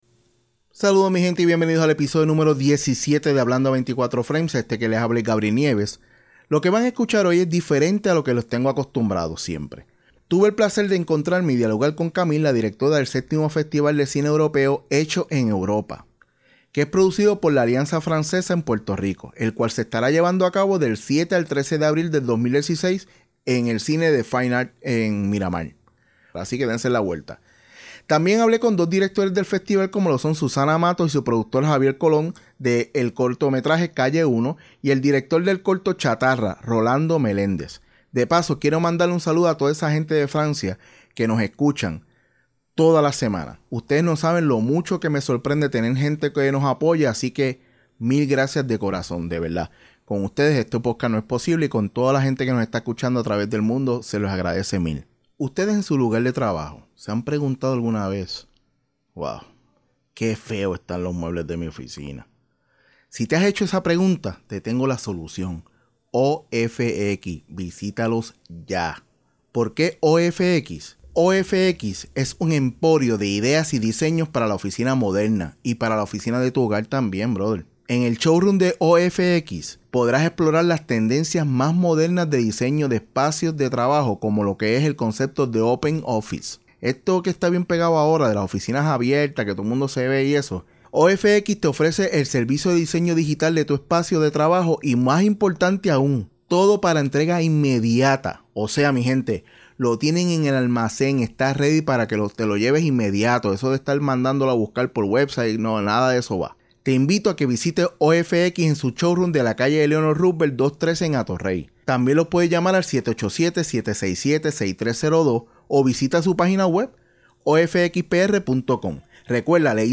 El episodio #17 es diferente a lo que los tengo acostumbrados ya que es la primera ves que salgo del estudio y que tengo 4 invitados a la misma ves.